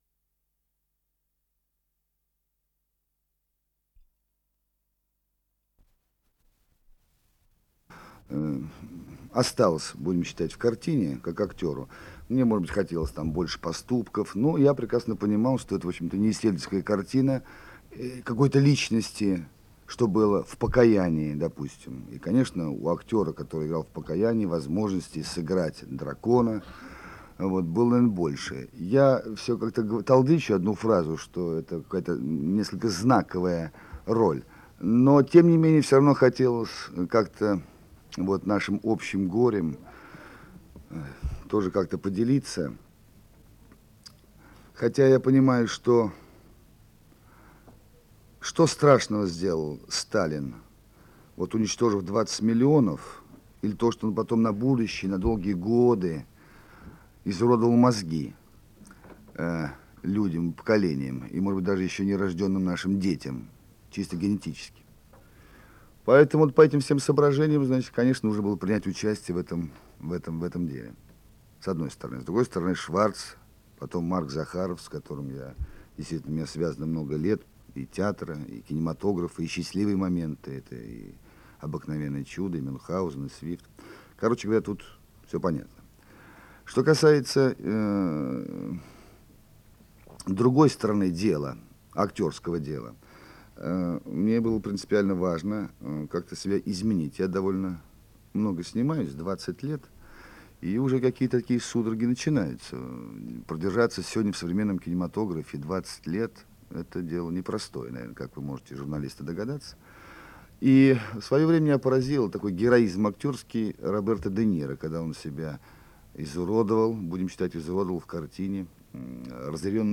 ДРА-001 — Отрывок пресс-конференции команды фильма